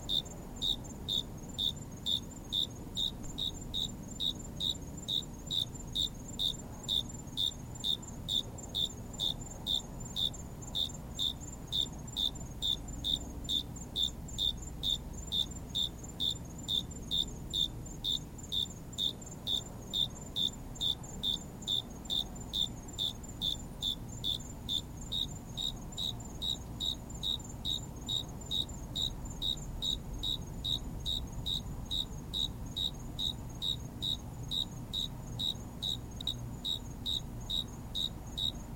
标签： 怪异 恐惧 恐怖 黑暗 呼吸 焦虑 入侵 邪恶 僵尸的快感 令人毛骨悚然 邪恶 恐怖 怪物 吓人
声道立体声